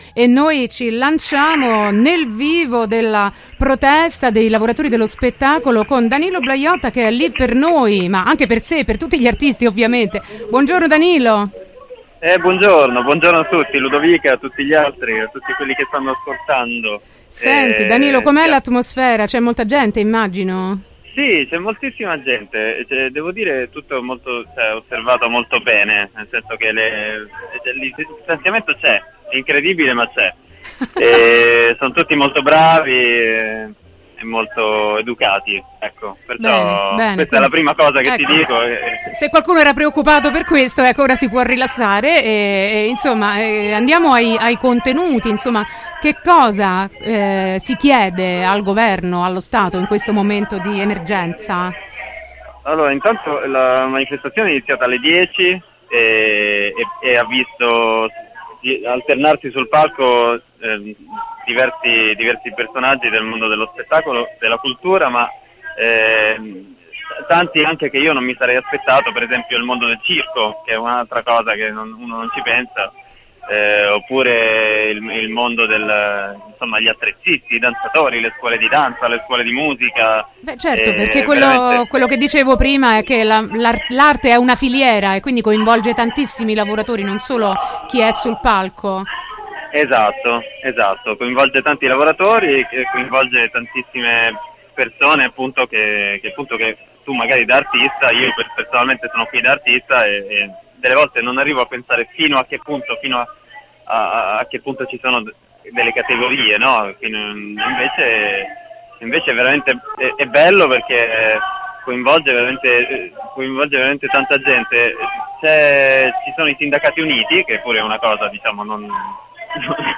La manifestazione dei lavoratori dello spettacolo: diretta da Montecitorio